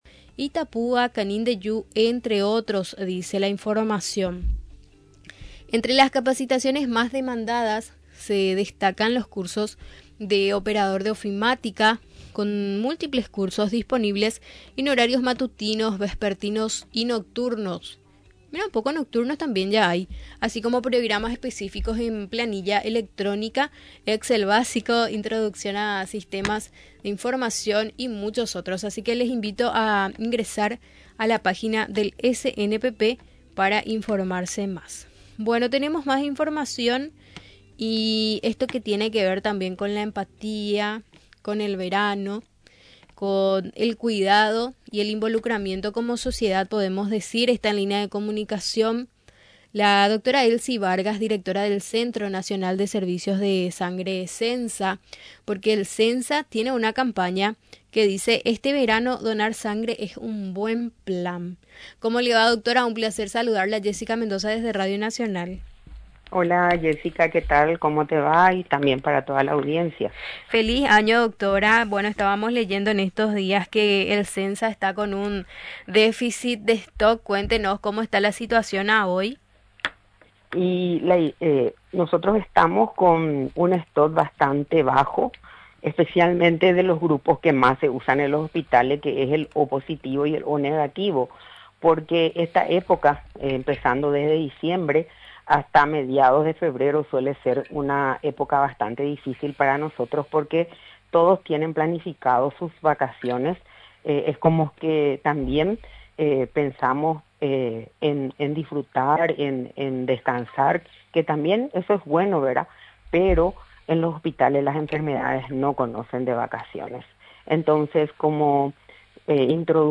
Durante la entrevista en Radio Nacional del Paraguay, mencionó los mitos sobre la donación de sangre. Además de significar los requisitos que debe tener en cuenta el donante.